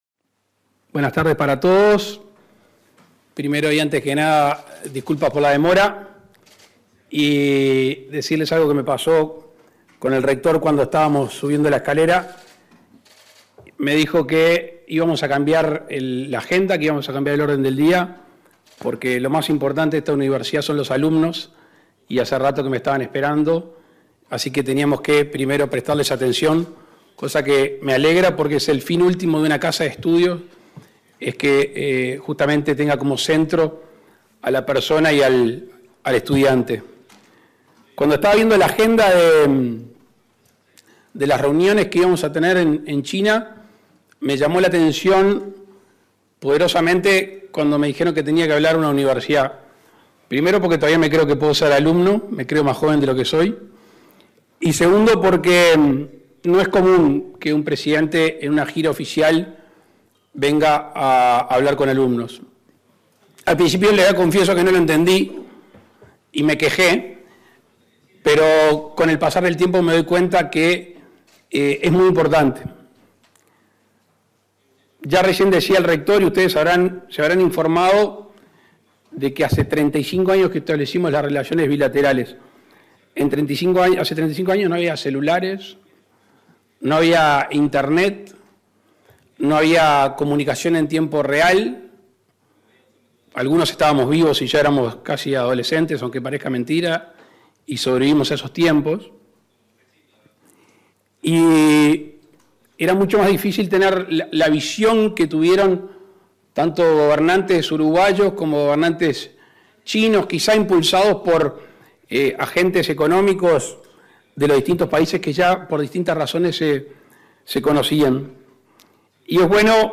La delegación visitó, este martes 21 de noviembre, la Universidad de Tsinghua, donde el mandatario realizó una disertación.